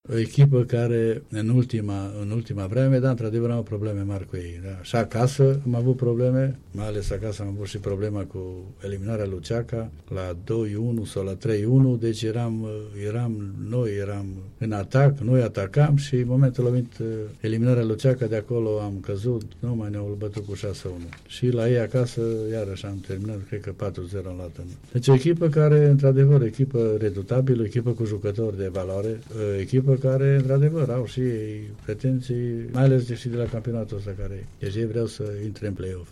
Despre forța adversarului din Cupa României a vorbit, la Unirea FM